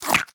sounds / mob / fox / eat3.ogg
eat3.ogg